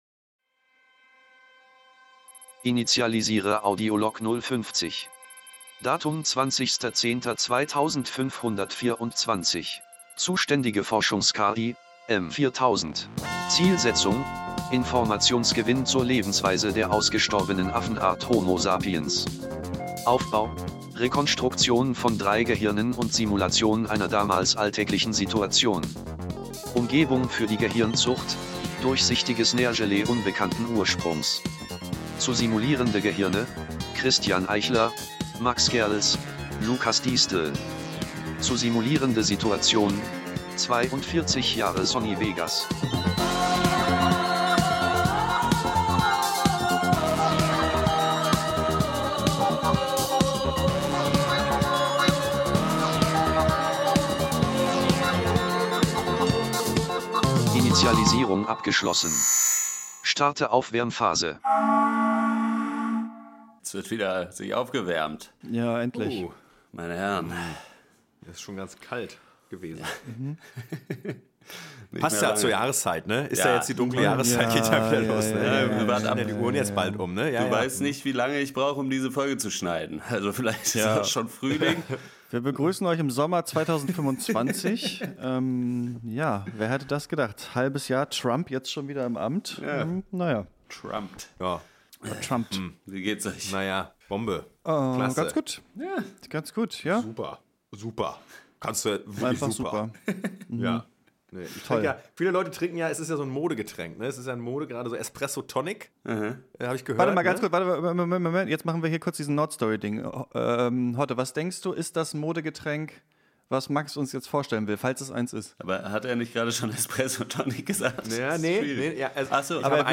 Live in Stuttgart ~ Gespräche in Aspik Podcast